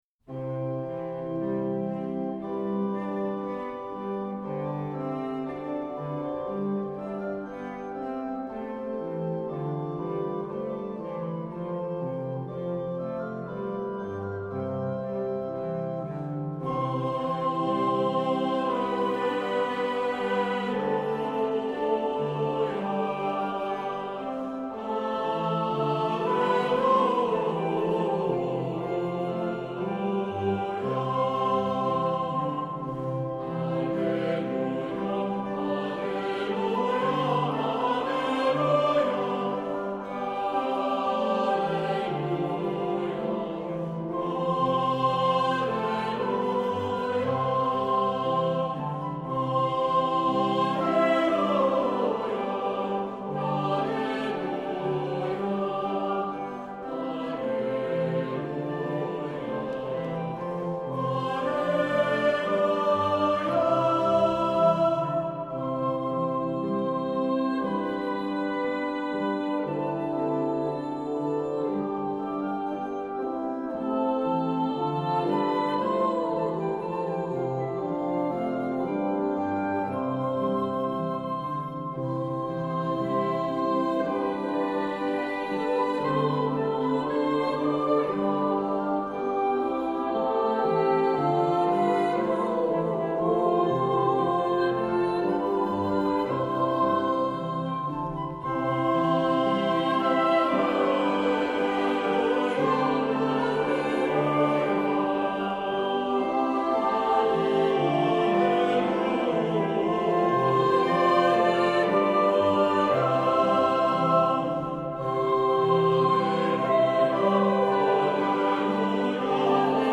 Accompaniment:      A Cappella
Music Category:      Christian